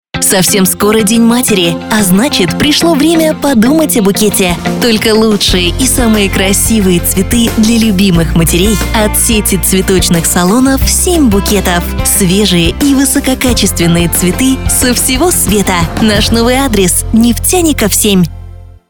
Началось размещение рекламы на радиостанции "Хит FM" компании "Семь Букетов" в г. Мегионе.